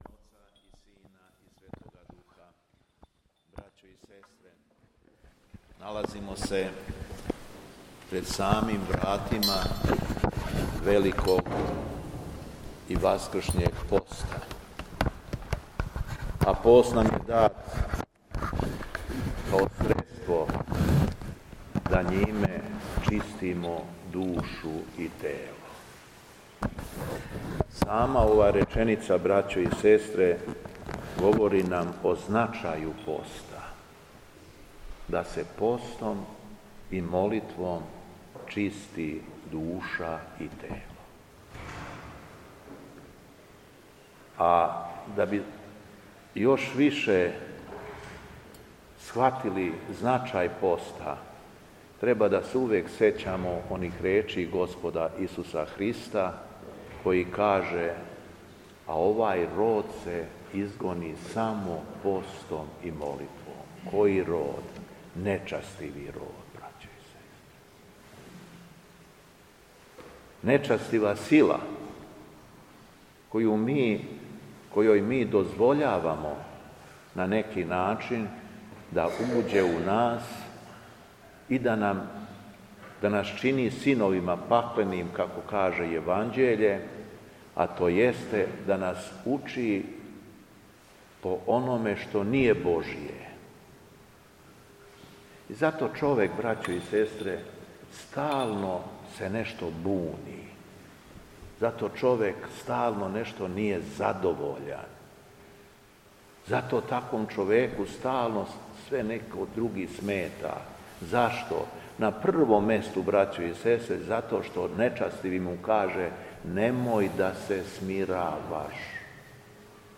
АРХИЈЕРЕЈСКА ЛИТУРГИЈА У ХРАМУ СВЕТОГ ВЕЛИКОМУЧЕНИКА ГЕОРГИЈА У ДРЕНУ
Беседа Његовог Високопреосвештенства Митрополита шумадијског г. Јована
У сиропусну недељу, недељу праштања 22. фебруара 2026. године, Његово Високопреосвештенство Митрополит шумадијски Господин Јован, служио је архијерејску Литургију у храму Светог великомученика Георгија у Дрену, надомак Лазаревца.